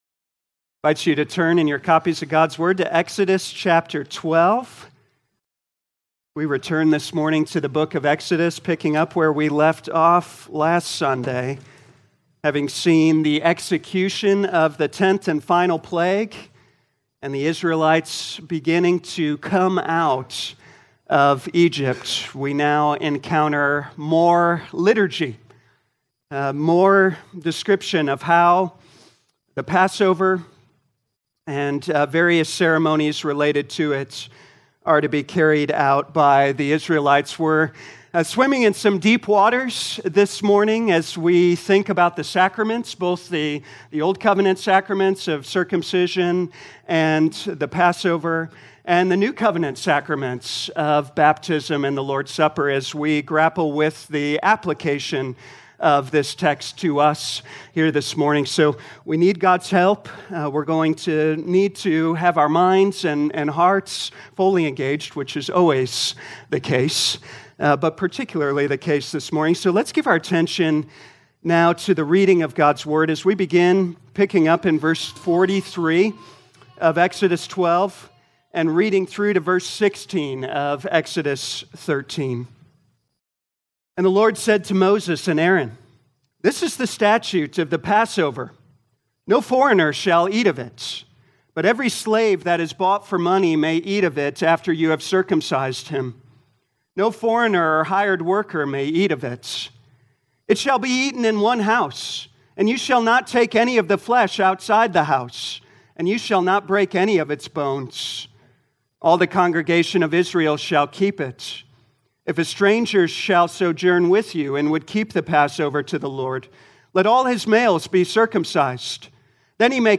2025 Exodus Morning Service Download